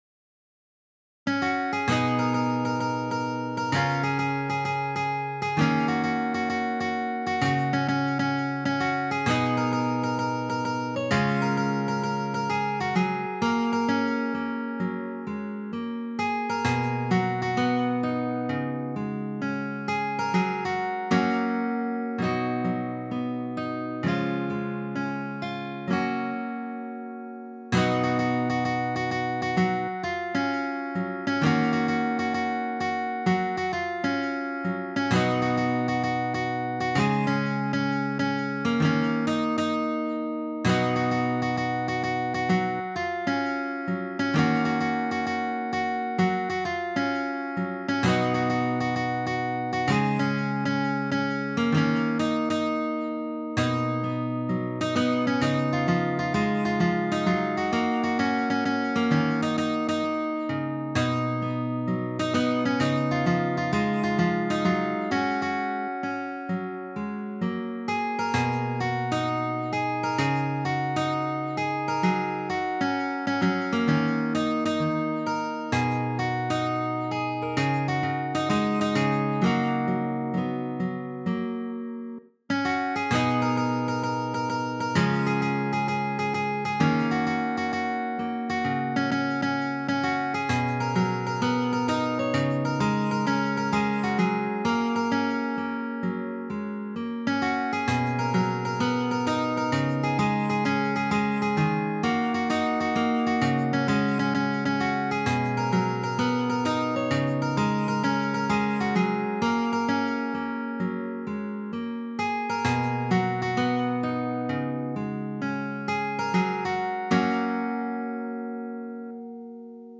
变调夹/指法：6品C调指法 曲谱类型：指弹谱